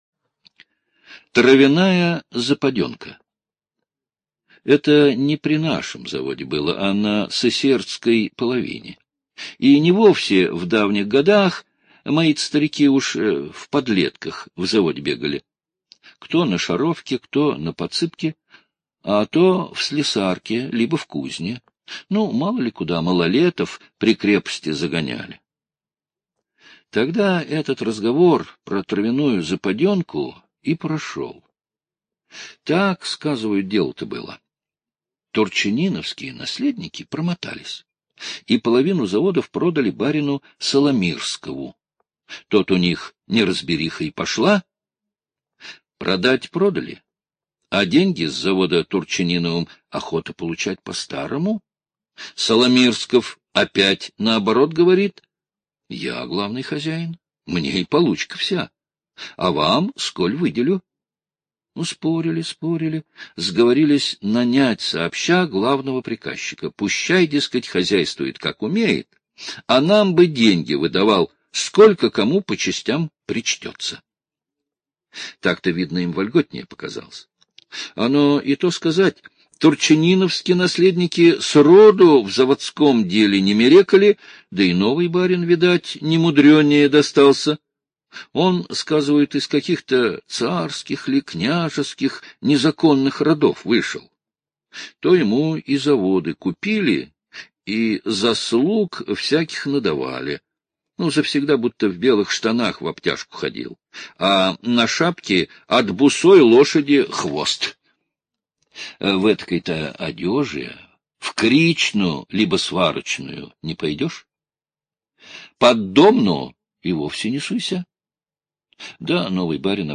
Травяная западенка - аудиосказка Павла Бажова - слушать онлайн